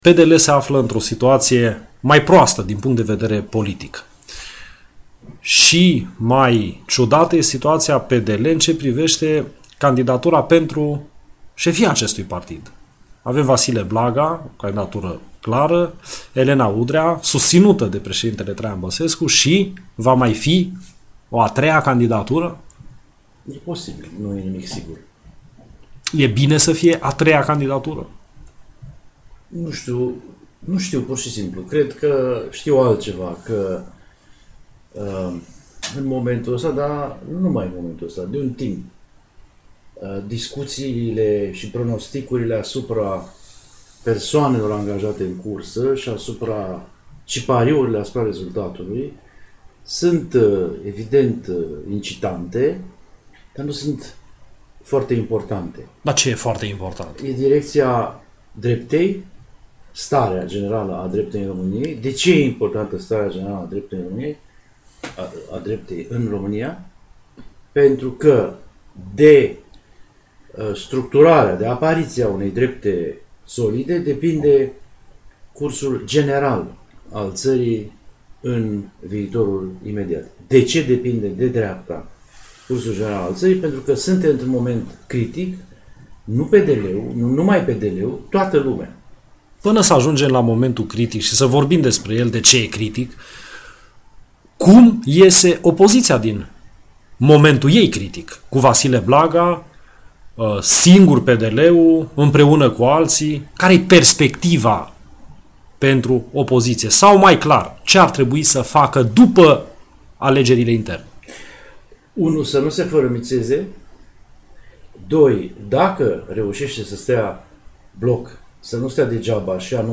Asculta interviul cu Traian Ungureanu in varianta AUDIO